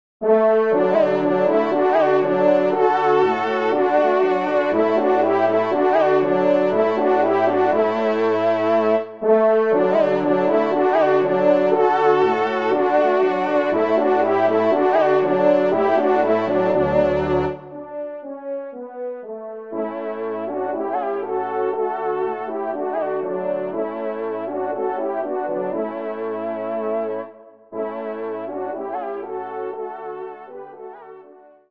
Genre : Divertissement pour Trompes ou Cors
Trompe 2